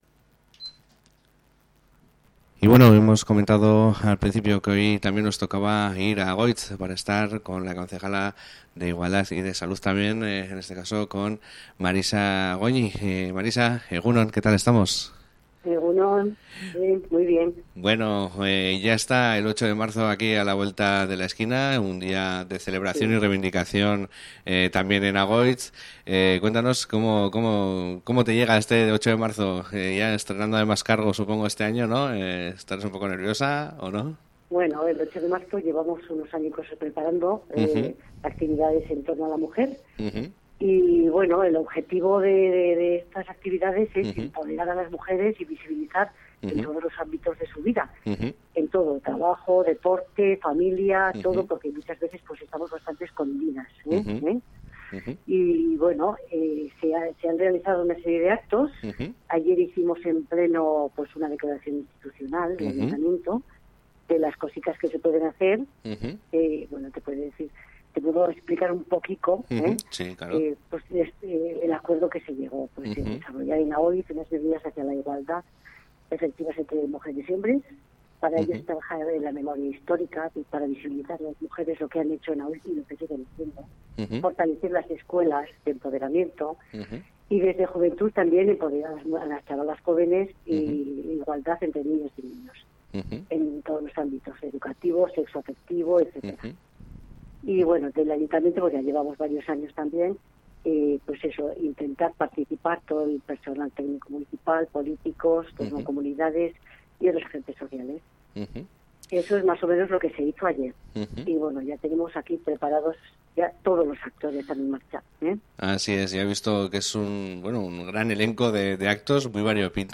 Igandean izanen da Emakumearen Eguna eta Euskal Herriko hainbat herri zein hirietan Agoitzen ere hainbat ekimen antolatu dituzte egun hau ospatu eta aldarrikatzeko. Guzti honetaz solasteko Marisa Goñi Berdintasun zinegotziarekin egin dugu topo, lehen eskutik jaso dezagun Agoitzen izanen den egitaraua.
Marisa Goñi Berdintasun zinegotzia Irati irratian